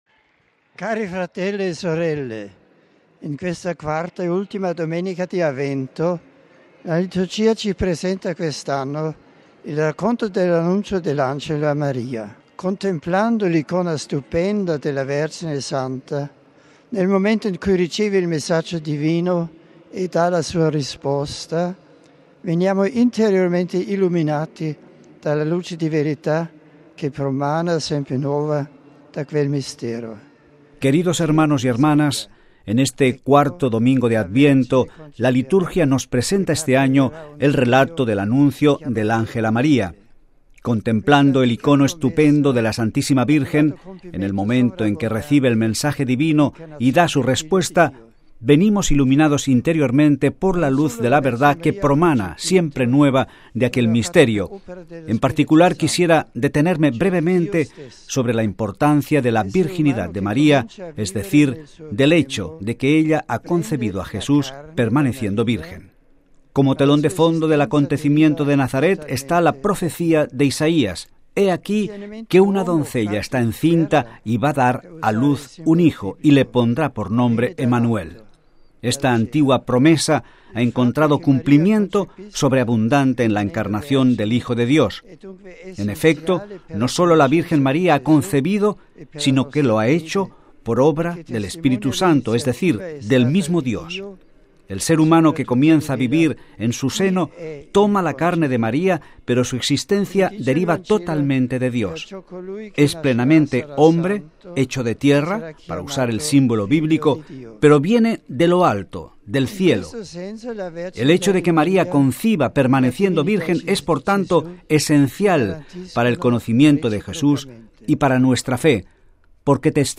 Texto completo de la alocucion del Santo Padre a la hora del ángelus dominical: